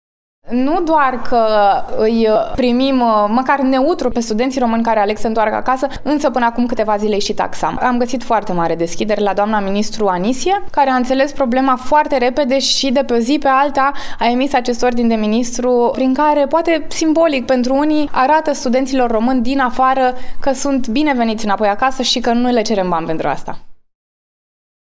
Taxele de echivalare și traducere a diplomelor studenților români ce se întorc din străinătate au fost eliminate, ca urmare a unei discuții avute cu ministrul Educației Monica Anisie, a declarat deputatul de Brașov, Mara Mareș.